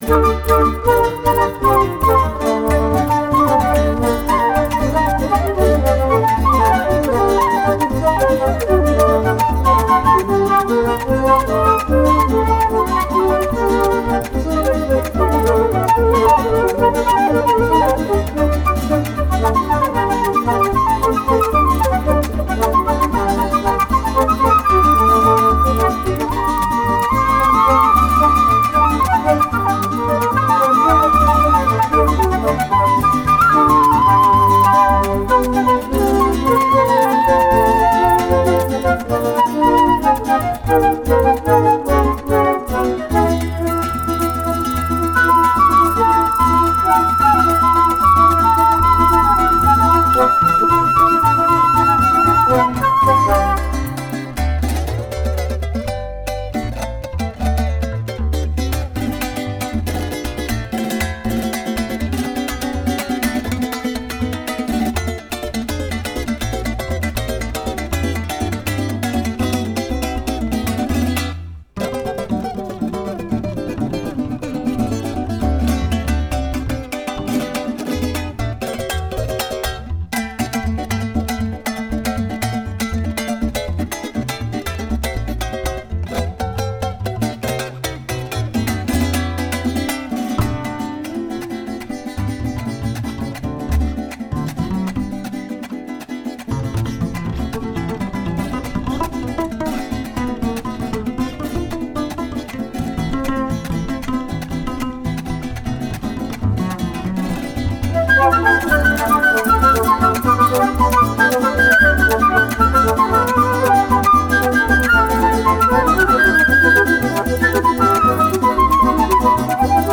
La música de América Latina